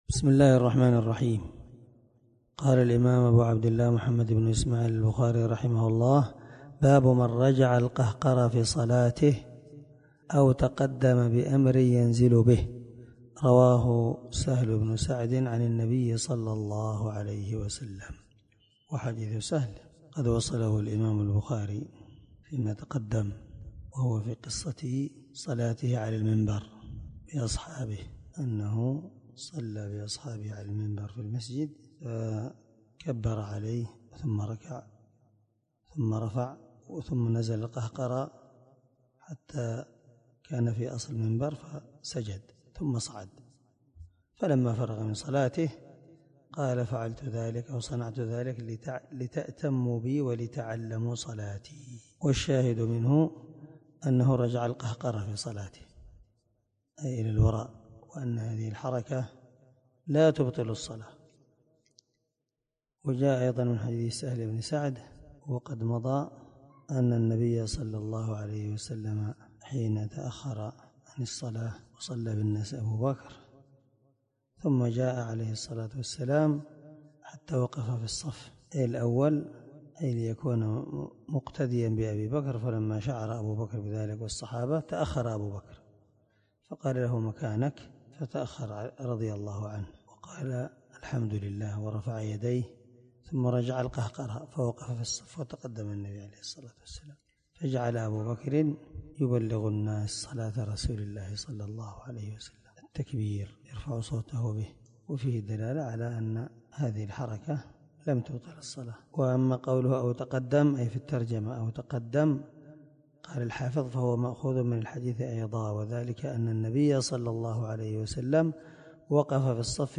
709الدرس_5من_شرح_كتاب_العمل_في_الصلاة_حديث_رقم1205_من_صحيح_البخاري